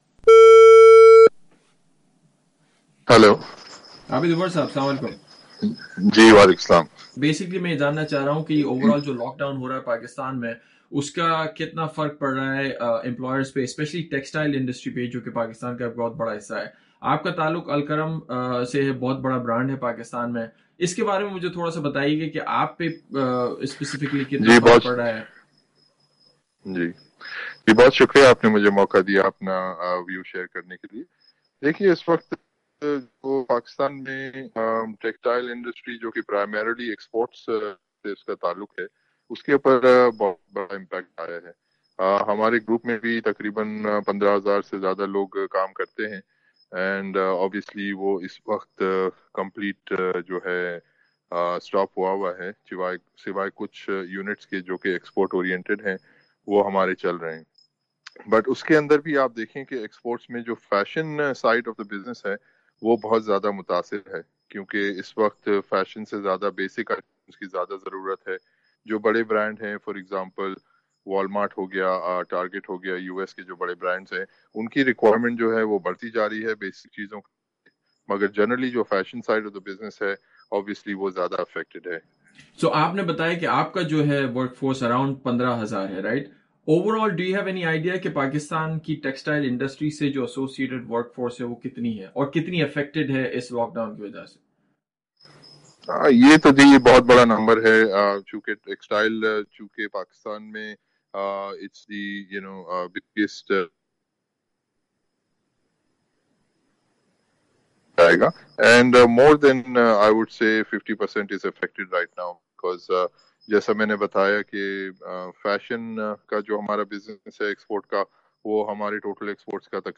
تفصیلی گفتگو۔